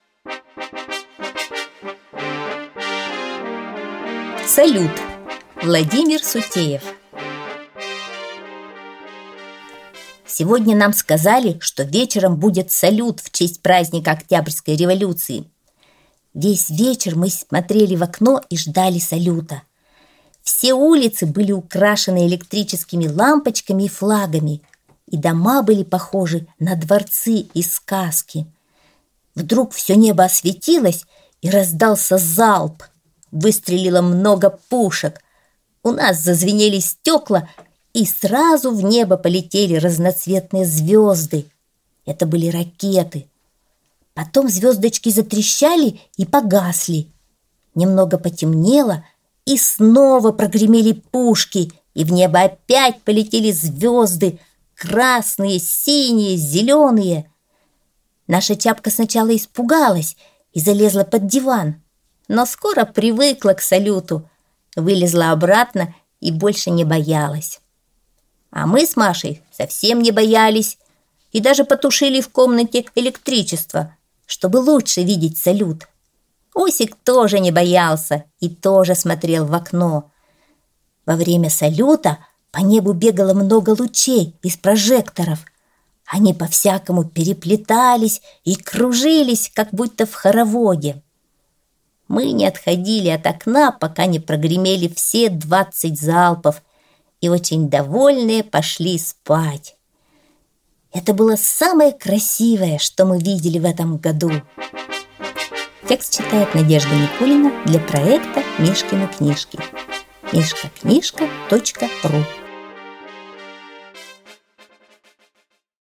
Аудиосказка «Салют »